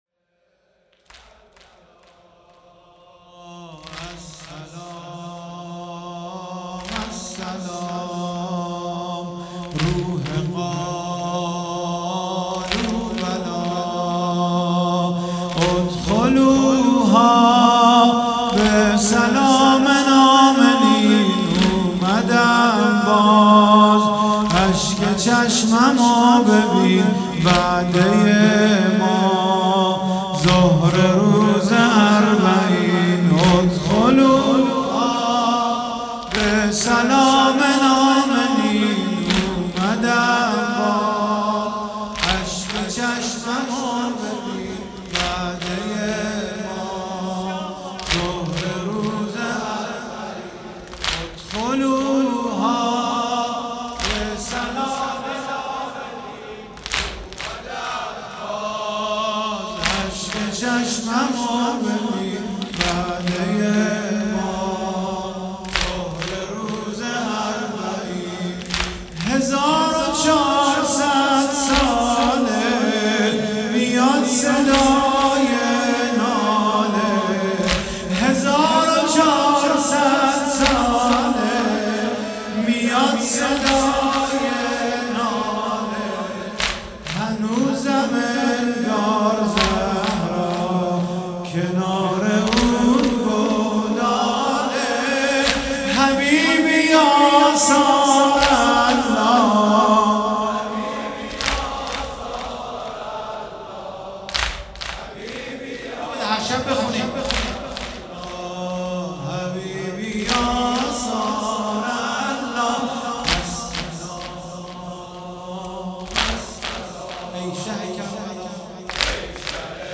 واحد - السلام السلام ای شه کربلا